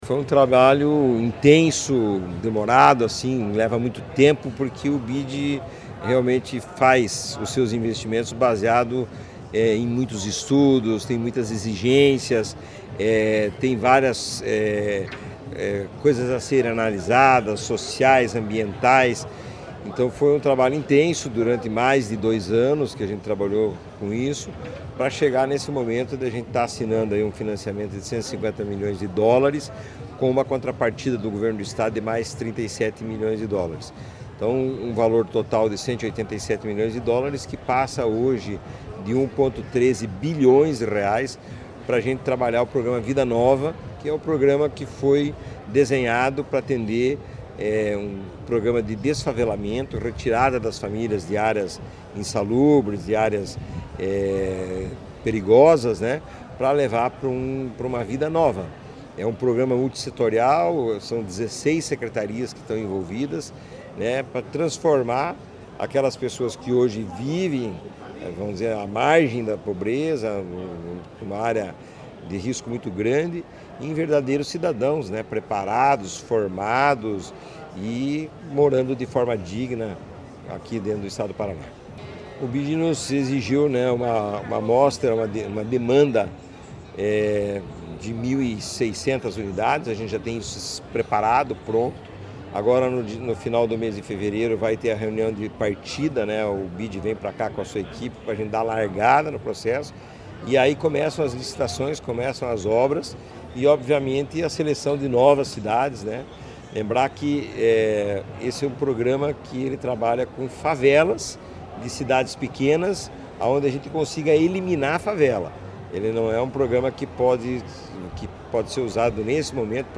Sonora do presidente da Cohapar, Jorge Lange, sobre o investimento de mais de R$ 1 bilhão para a construção de 6 mil casas a famílias vulneráveis